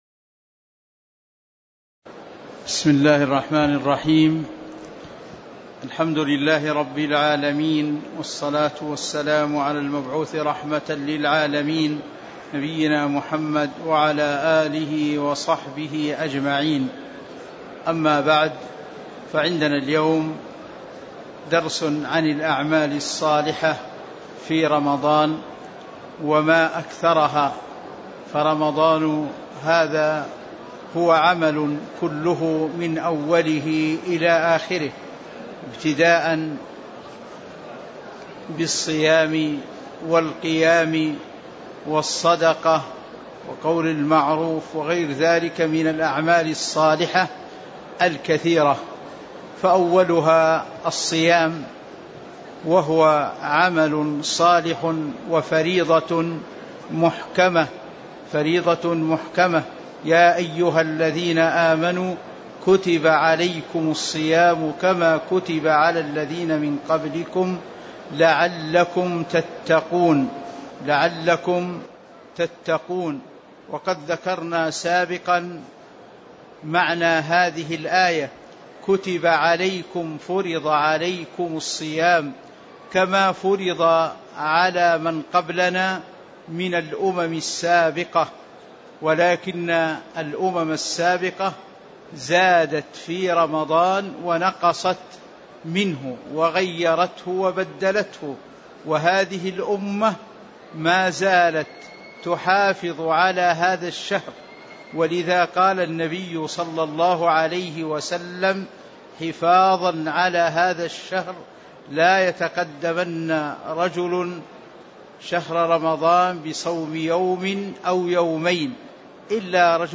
تاريخ النشر ٤ رمضان ١٤٤٤ هـ المكان: المسجد النبوي الشيخ